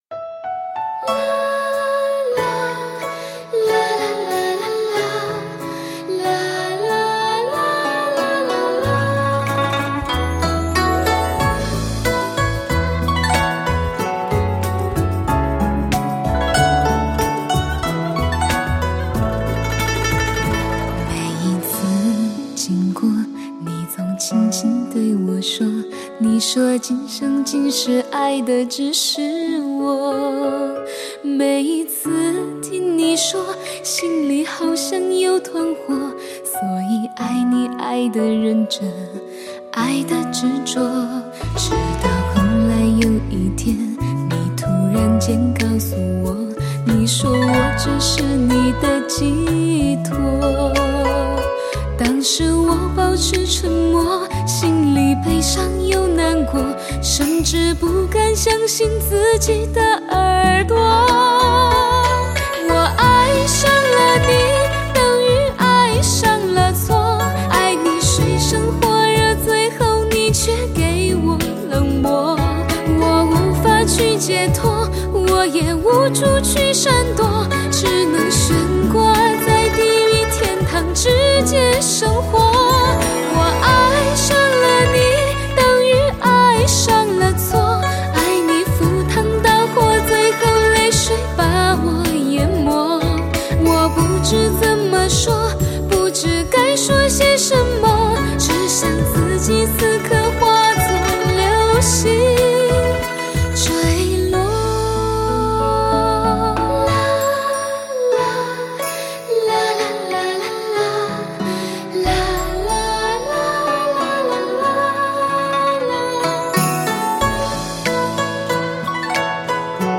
台湾白金录音棚录制